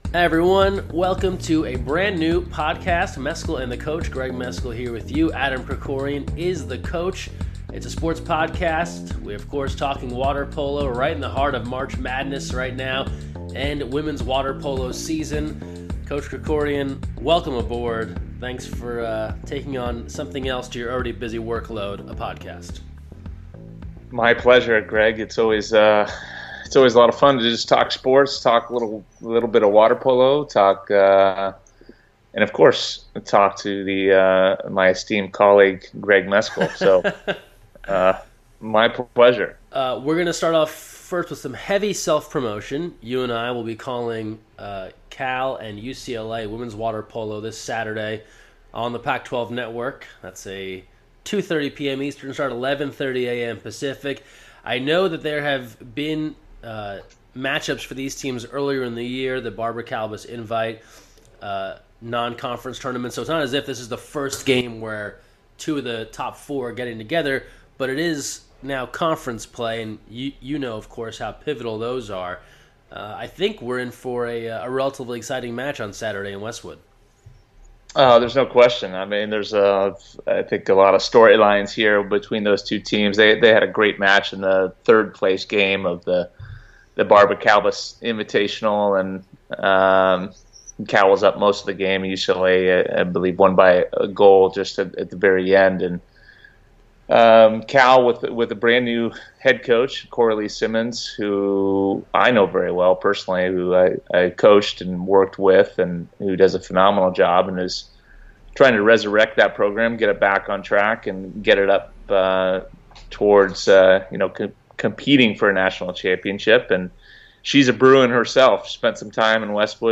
conversation podcast